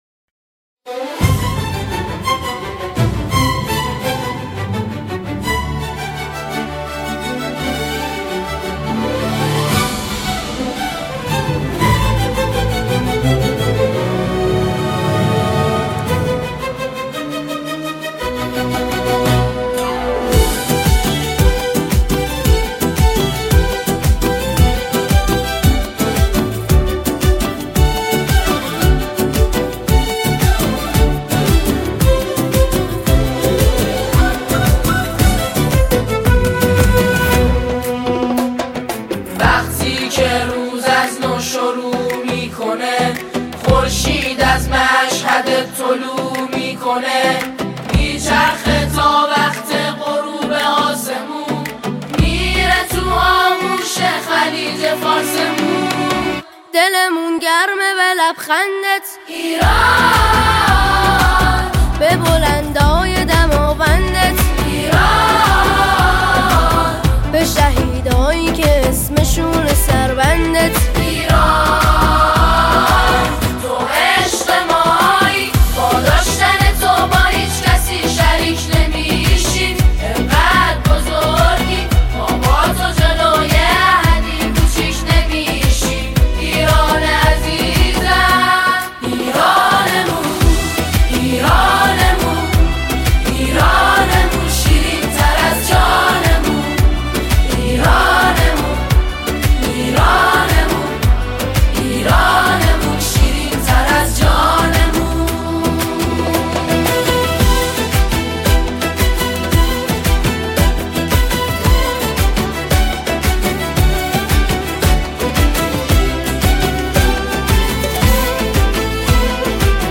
ژانر: سرود ، سرود انقلابی ، سرود مناسبتی